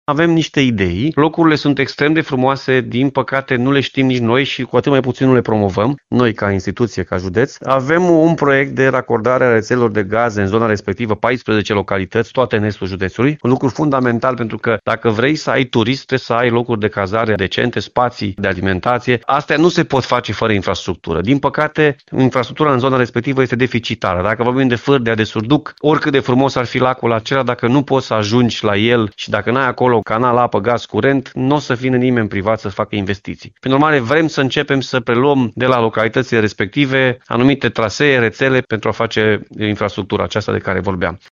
Președintele Consiliului Județean Timiș, Alfred Simonis, spune că se analizează posibilitatea preluării unor rețele de utilități de către județ pentru a fi dezvoltate mai rapid.